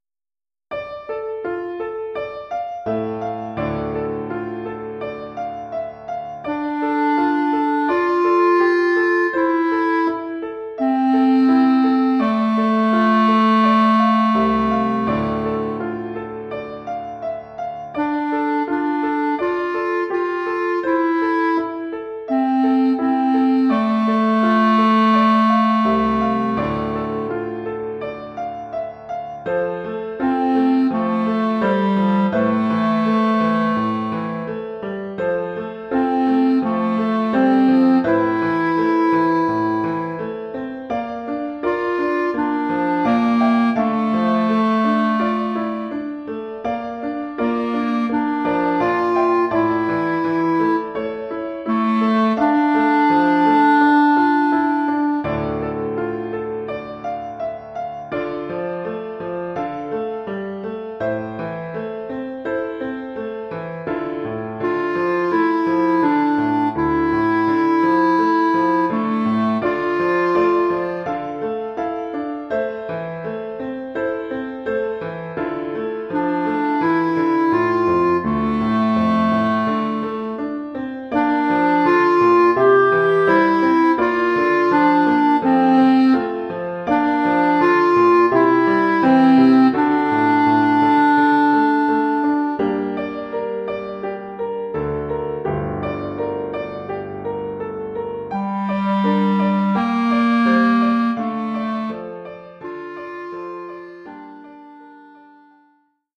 Formule instrumentale : Clarinette et piano
Oeuvre pour clarinette et piano.